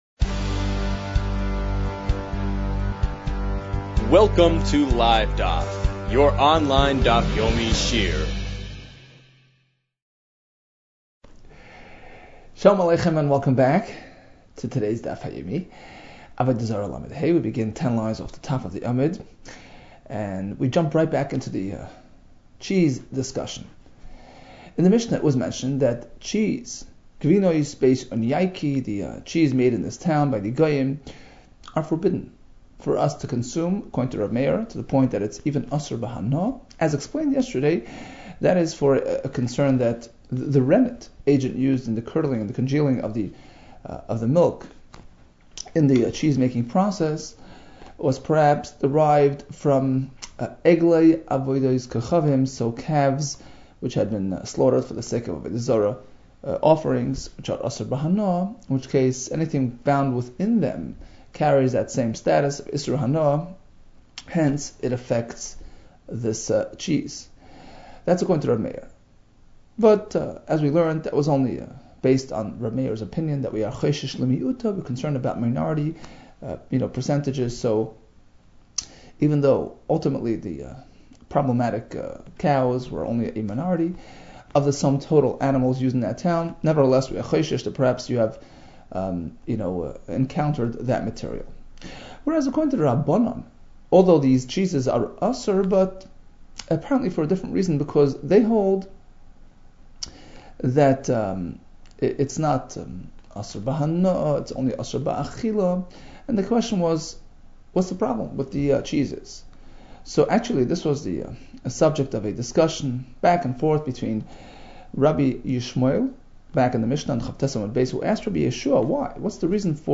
Avodah Zarah 35 - עבודה זרה לה | Daf Yomi Online Shiur | Livedaf